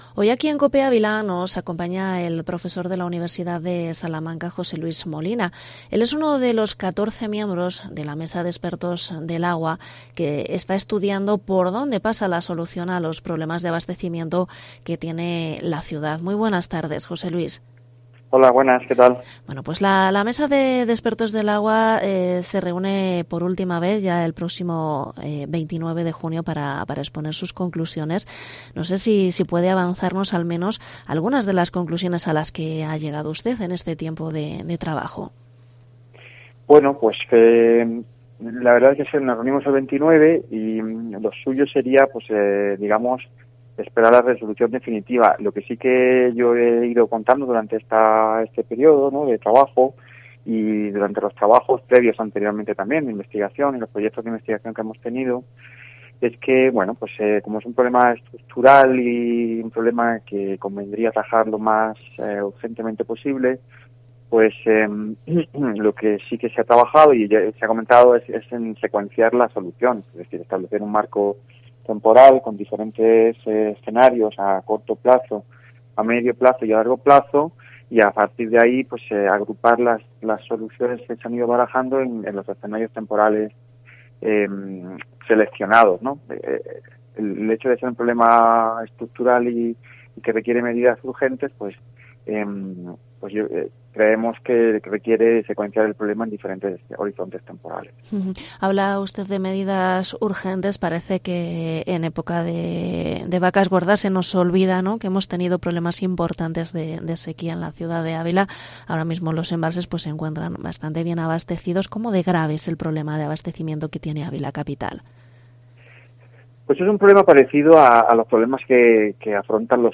Entrevista al profesor de la USAL